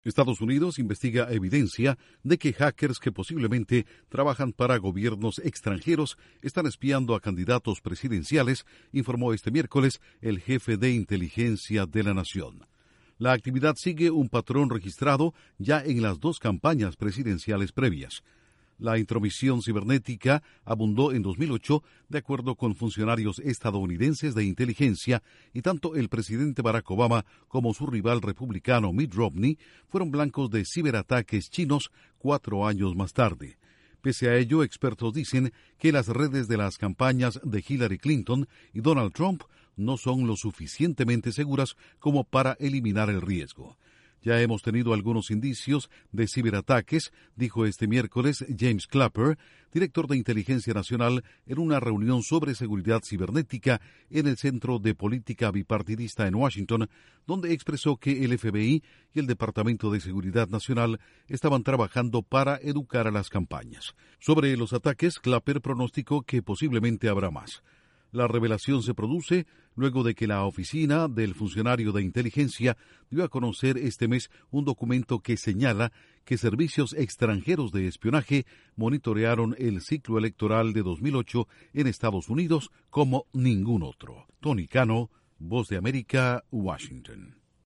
Investigan posible espionaje cibernético a precandidatos presidenciales de Estados Unidos. Informa desde la Voz de América